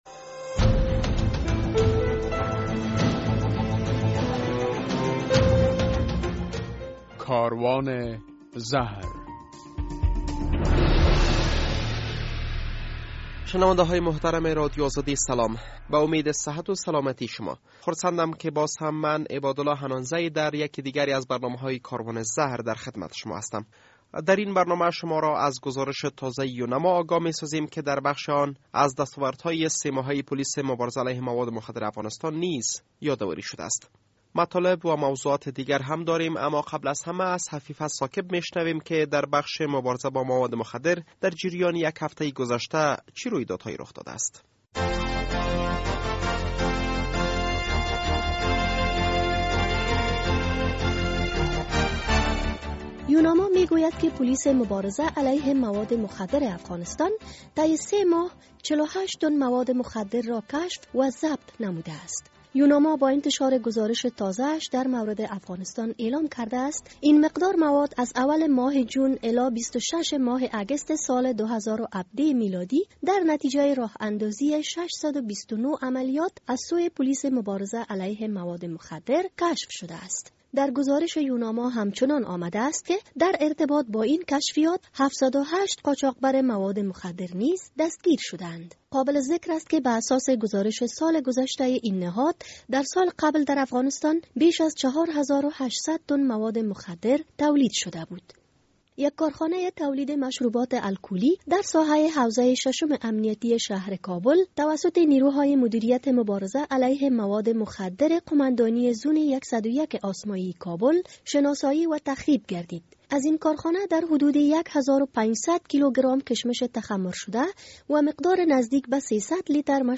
در برنامه این هفته کاروان زهر: در نخست خبرها، بعداً گزارش در مورد راپور سه ماهه یونما در باره مواد مخدر در افغانستان ...